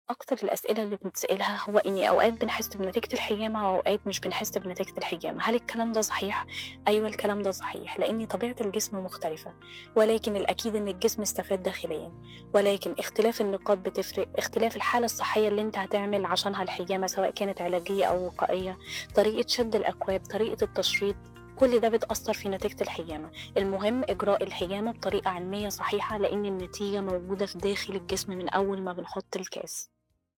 فويس-الدكتورة-بتتكلم-عن-الحجامة.mp3